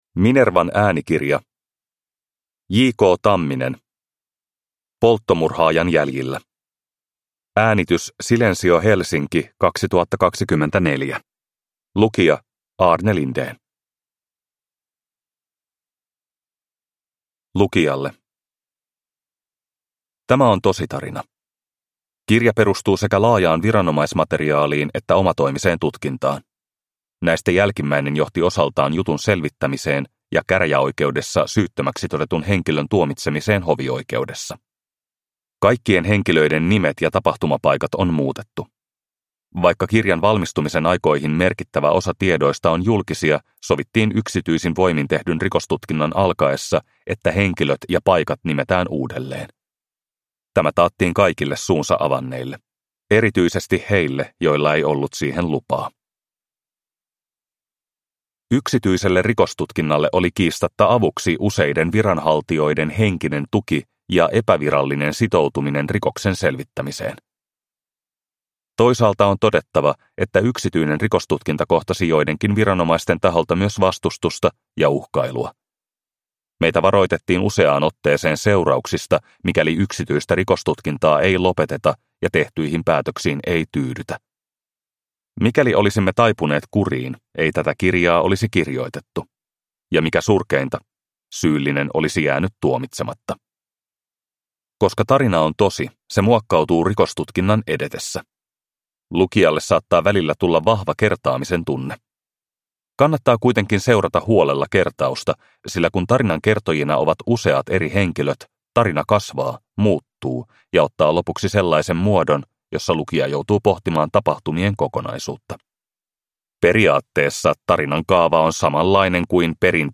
Polttomurhaajan jäljillä – Ljudbok